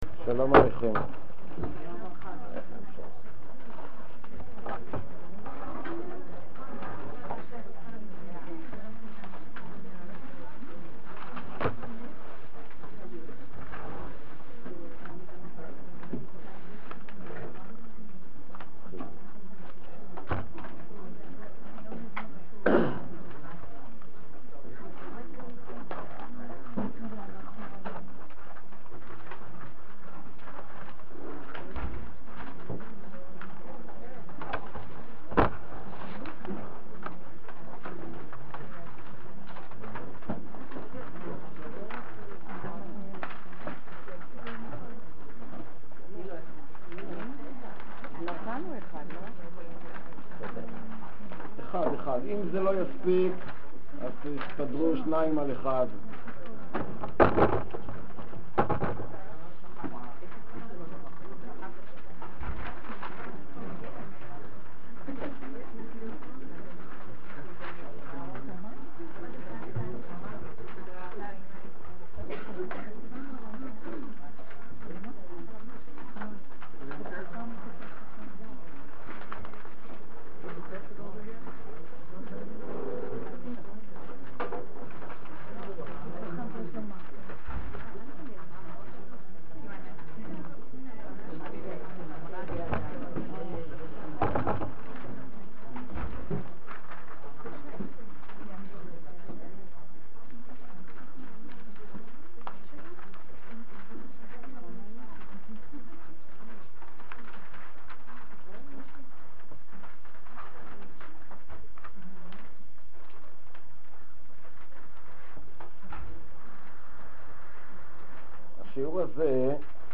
Yeshivat Chovvei Torah Yom Iyyun l'Tanach